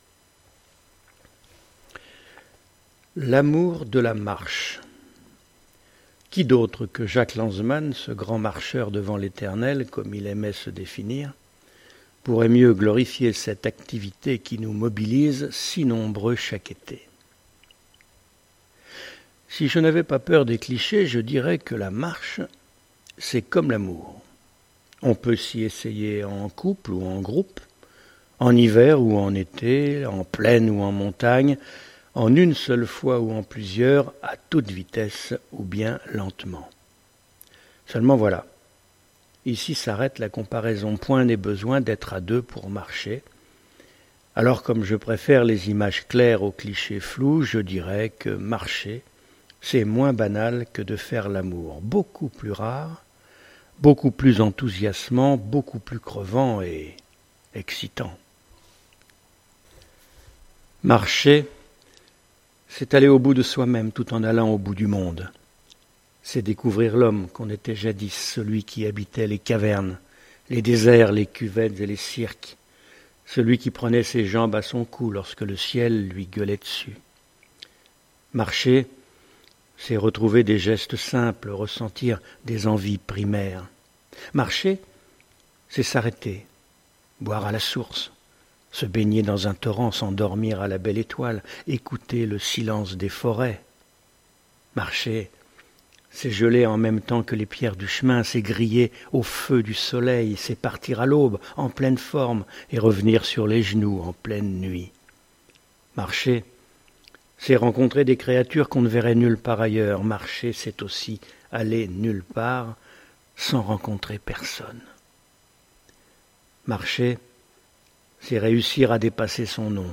voix naturelle, nerveuse, peut être jouée, idéale pour livres audios et voice over
Sprechprobe: eLearning (Muttersprache):